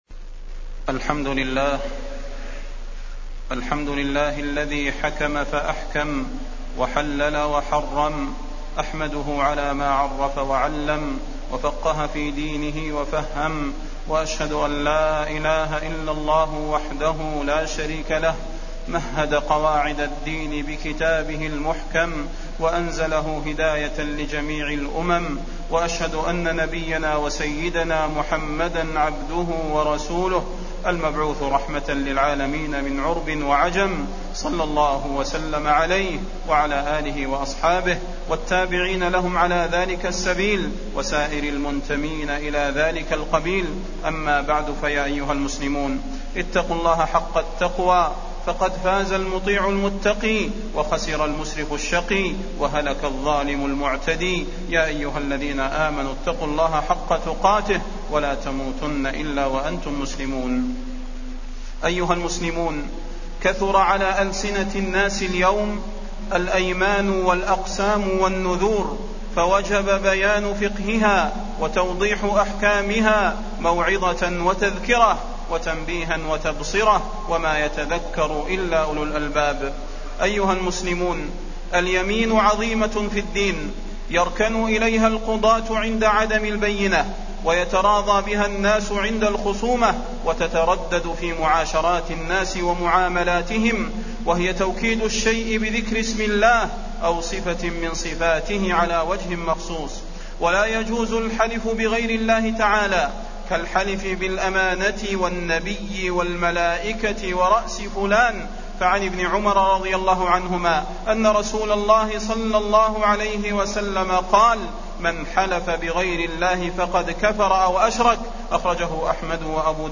تاريخ النشر ٢٥ جمادى الأولى ١٤٢٩ هـ المكان: المسجد النبوي الشيخ: فضيلة الشيخ د. صلاح بن محمد البدير فضيلة الشيخ د. صلاح بن محمد البدير كثرة الحلف